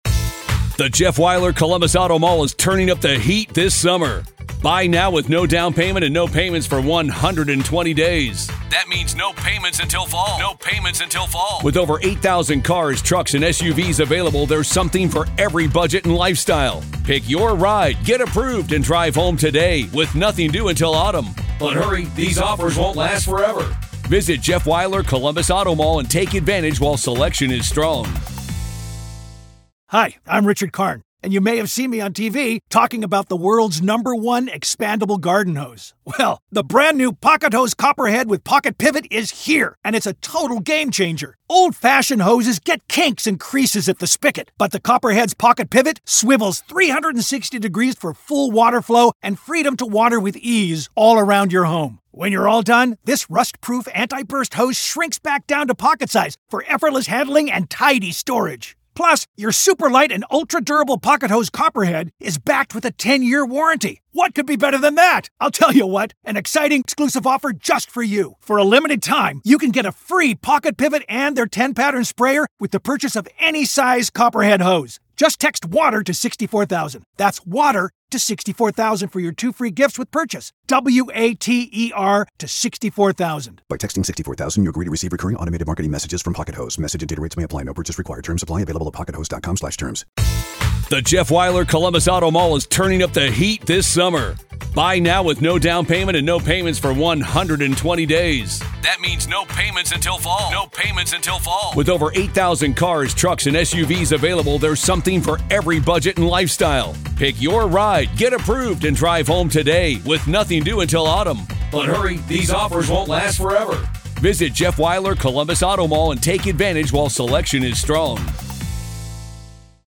This is live audio from the courtroom in the high-profile murder retrial of Karen Read in Dedham, Massachusetts.